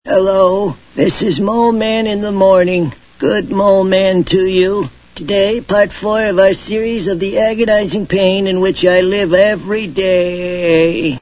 Voiced by Dan Castellaneta